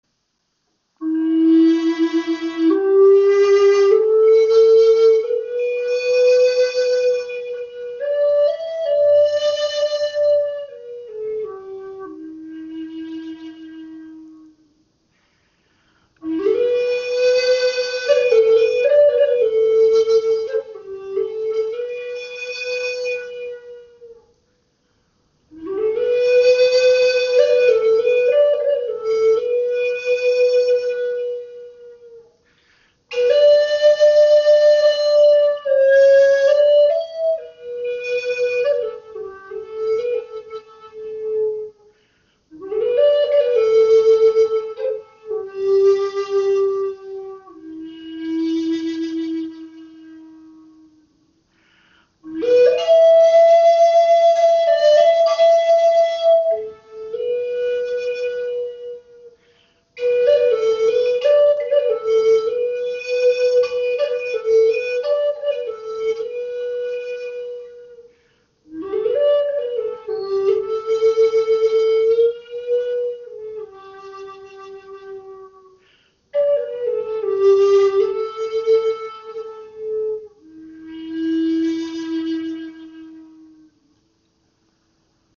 Klangbeispiel
Diese Gebetsflöte in E ist auf 432 Hz gestimmt. Sie wurde aus dem Holz des Mammutbaums geschaffen und abschliessend geölt, so dass sie ein seidenglänzendes Finish hat.